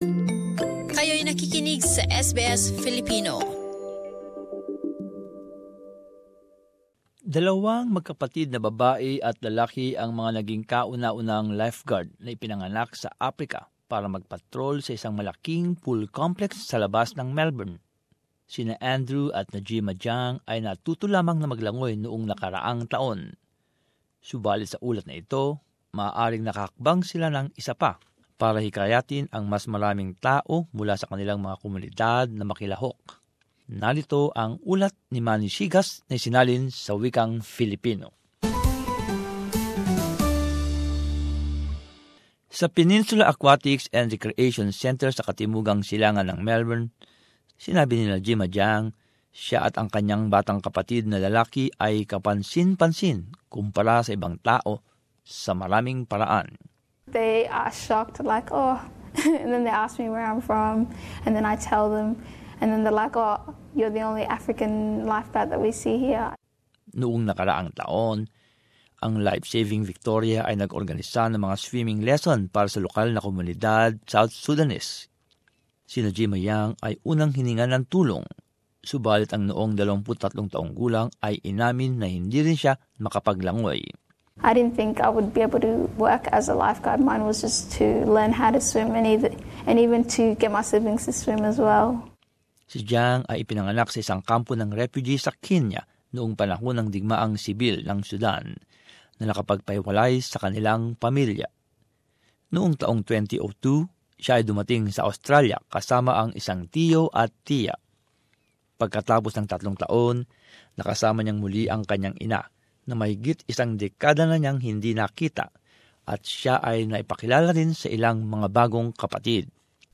But as this report shows, they have taken it one step further to encourage more people from their community to get involved.